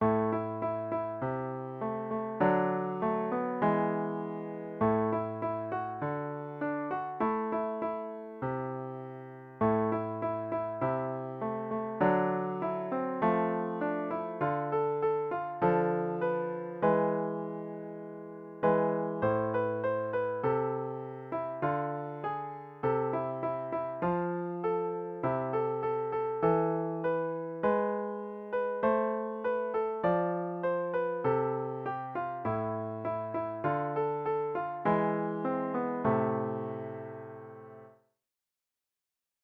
sound | piano 1